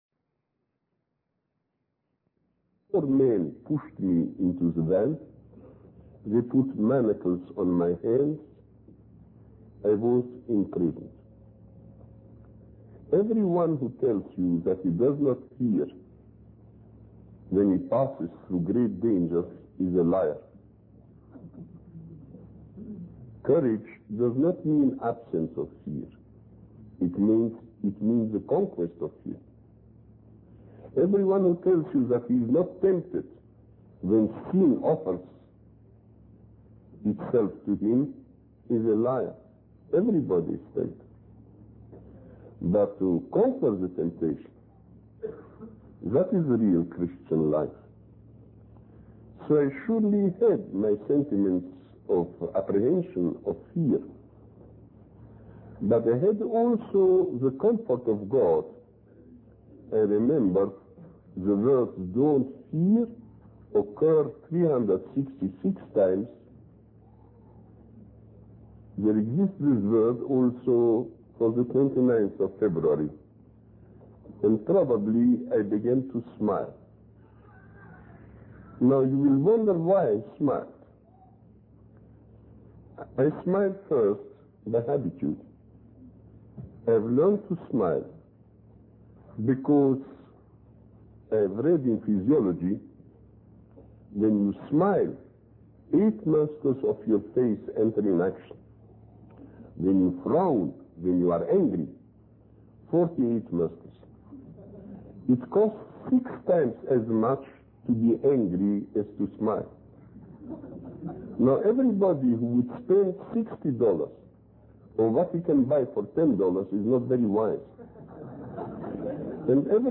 In this sermon, the preacher shares a story of a man who had forgotten his purpose in life and became consumed by worldly desires.